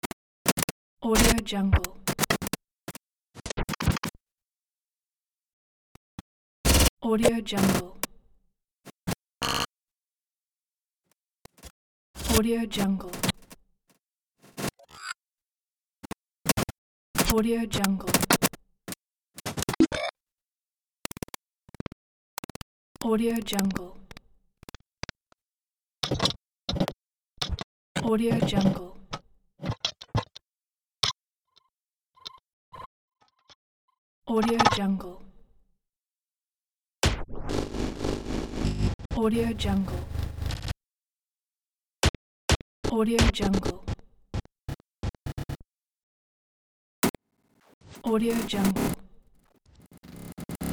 دانلود مجموعه افکت صوتی صدای‌ خطا
یک گزینه عالی برای هر پروژه ای است که به بسته های صوتی و جنبه های دیگر مانند نقص، آینده نگری و دیجیتال نیاز دارد.